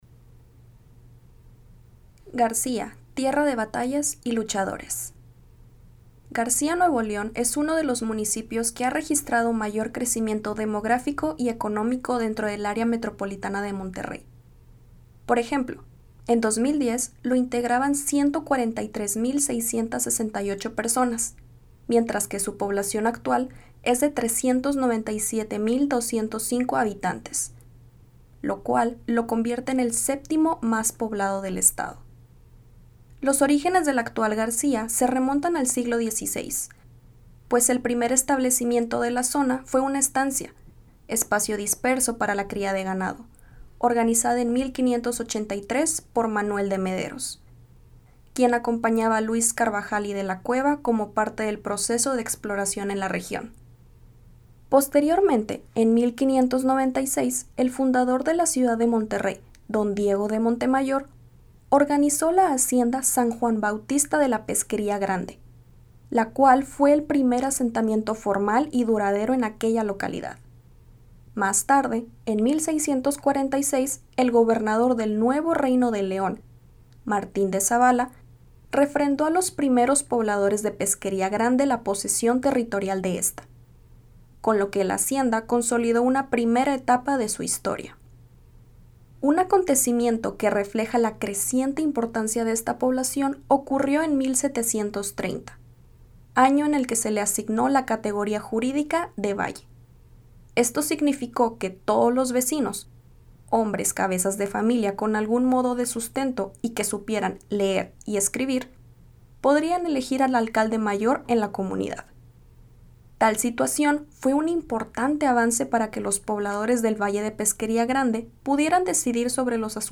Audio narración